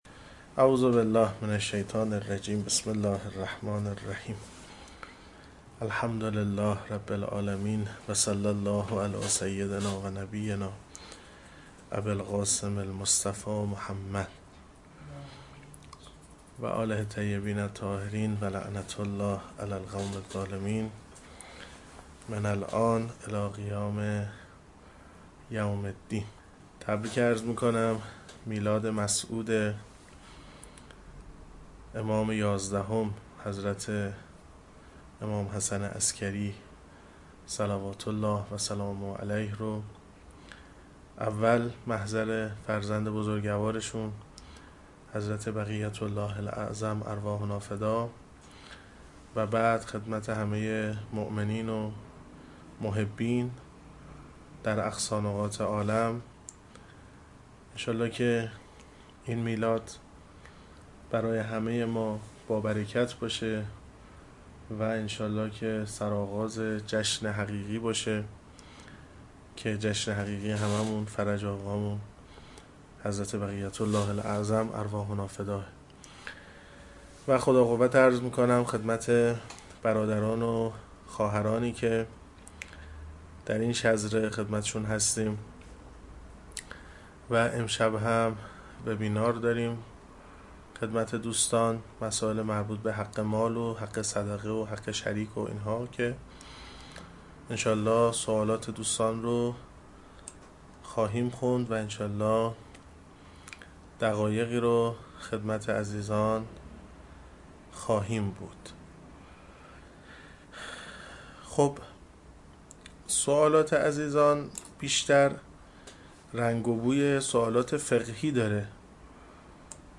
بدهکار خودت نباش! (حقوق مالی) - جلسه-پرسش-و-پاسخ